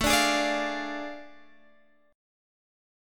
Ddim/A chord